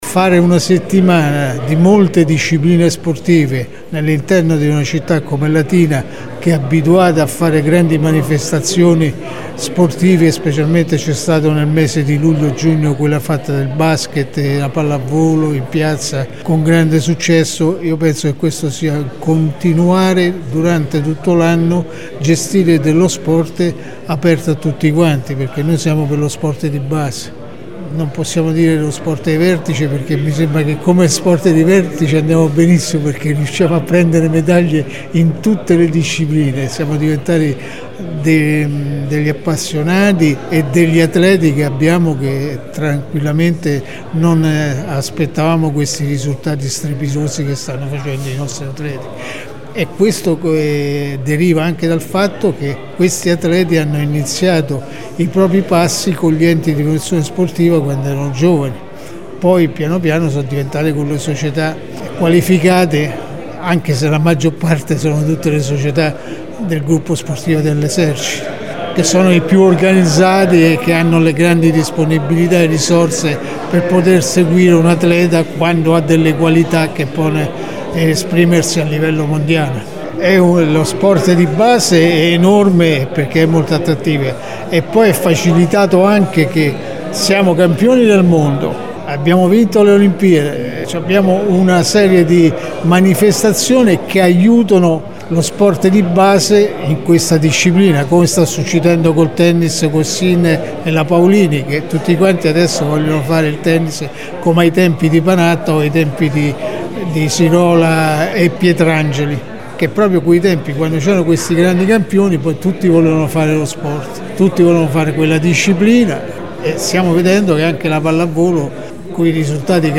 Ieri pomeriggio, presso la sala De Pasquale del Comune, la conferenza stampa di presentazione della prima edizione del Torneo di Pallavolo “Città di Latina”, che prenderà il via oggi con il Villaggio Europeo dello Sport.